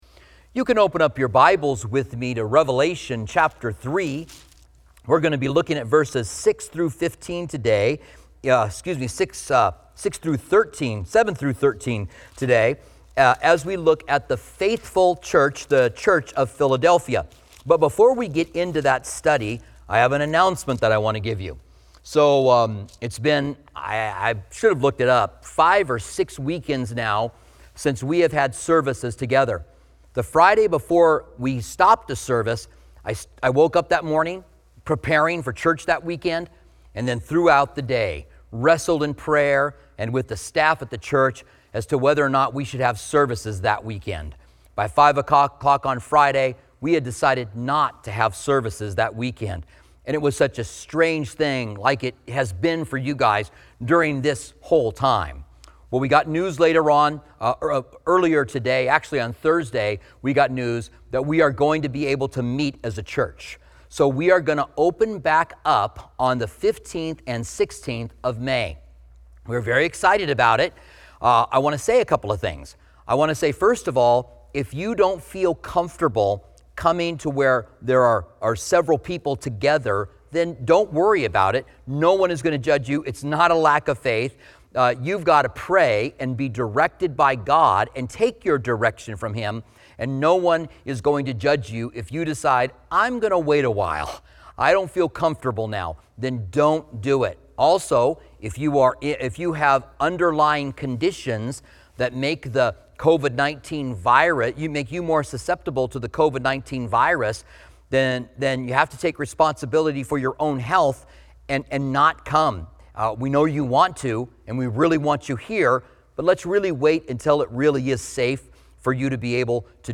preaching on the faithful church of Philadelphia in Revelation 3:7-13